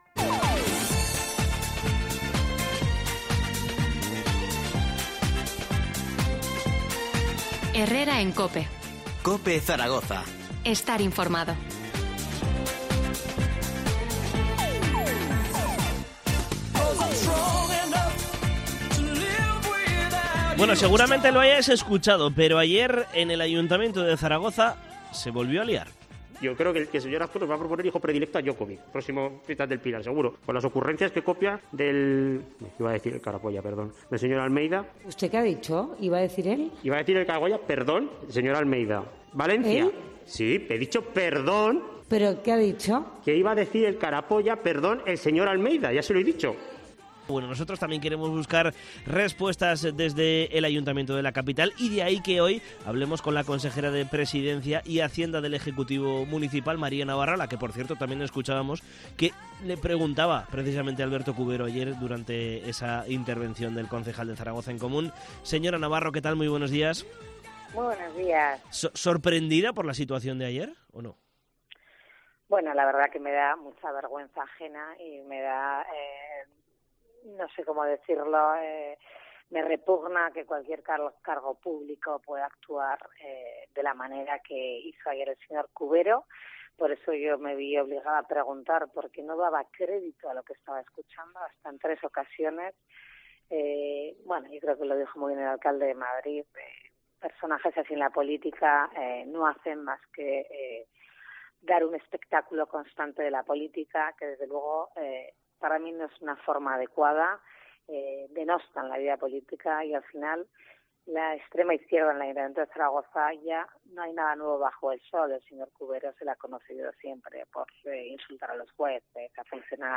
Entrevista a María Navarro, portavoz de gobierno del Ayuntamiento de Zaragoza